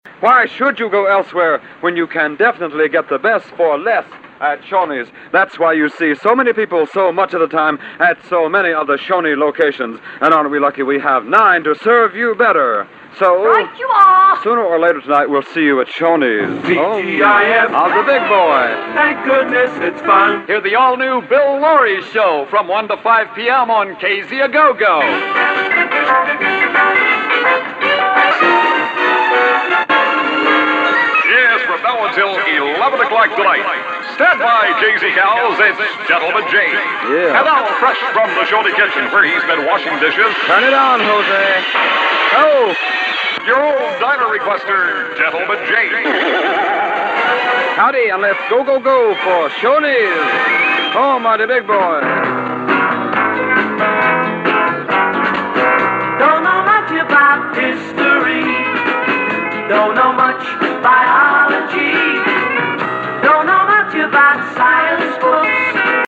Commercials, ID's, and just nonsense .
Shoneys and Station Spots
Dedications